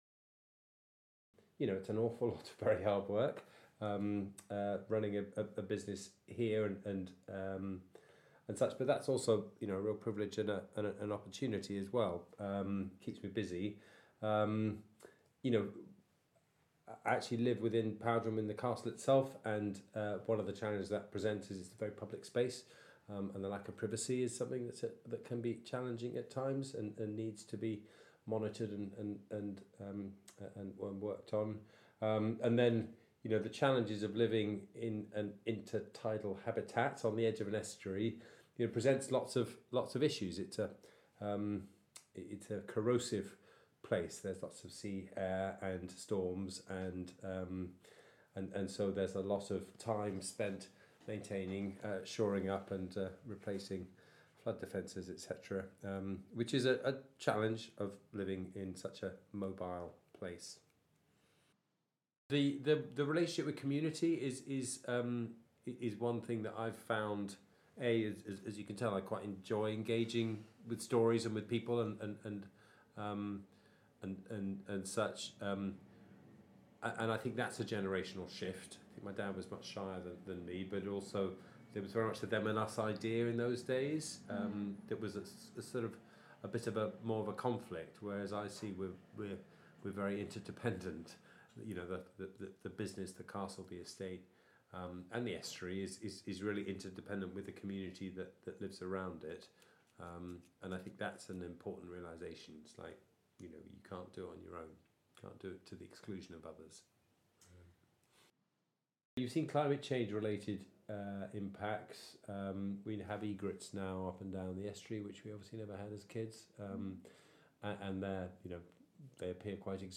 Here, Charles reflects on the interconnectedness of Powderham Estate with the land and communities surrounding it.